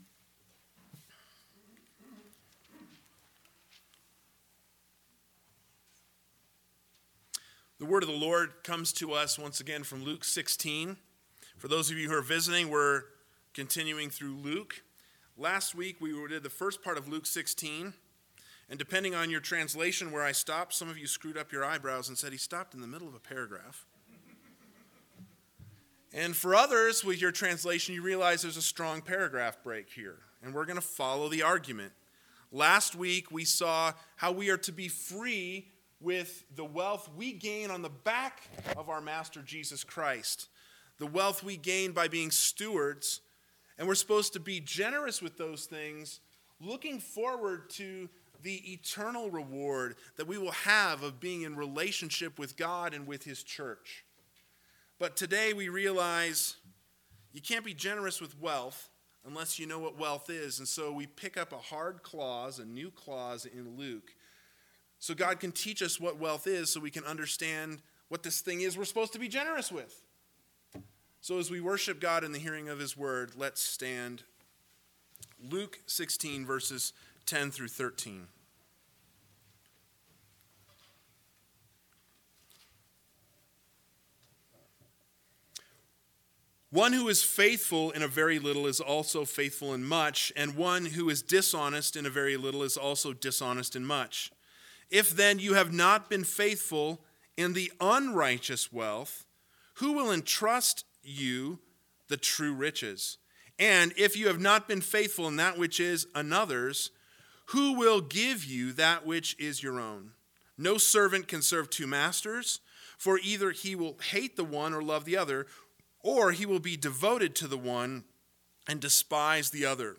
AM Sermon